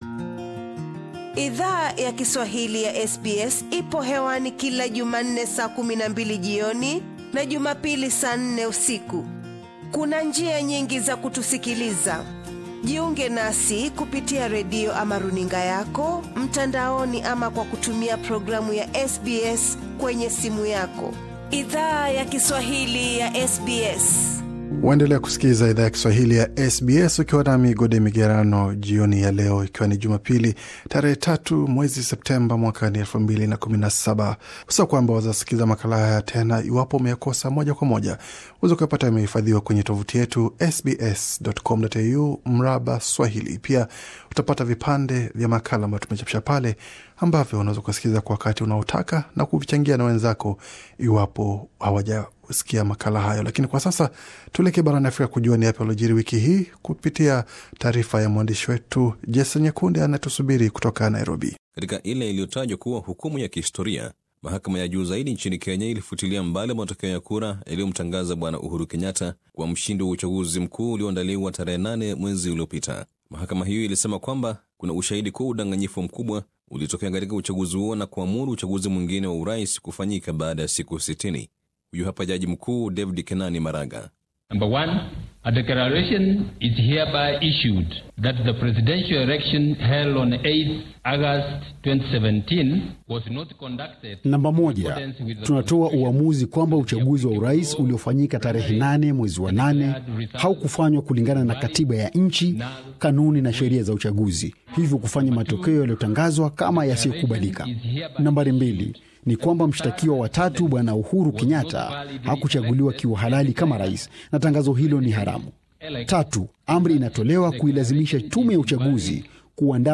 Mengi yalijiri barani Afrika wiki hii, bofya hapo juu usikize baadhi ya taarifa muhimu zilizo jiri wiki hii kama zilivyo simuliwa na mwandishi wetu kutoka mjini Nairobi, Kenya.